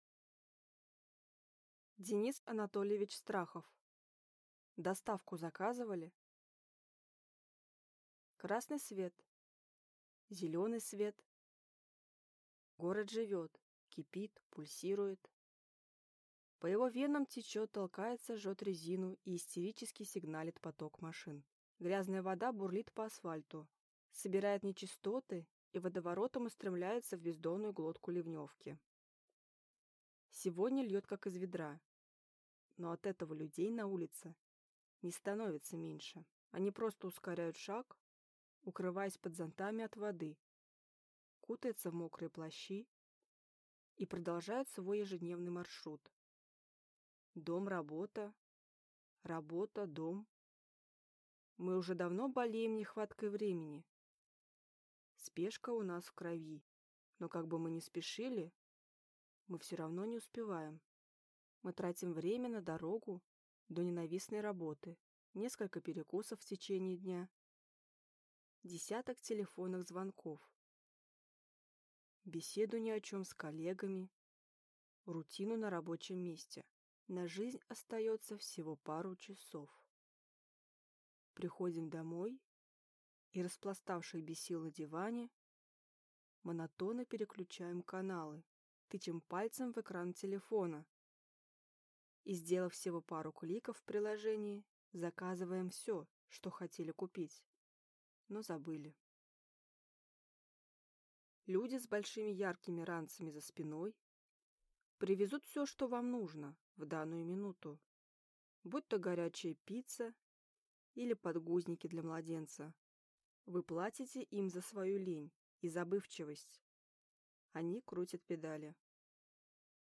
Аудиокнига Доставку заказывали?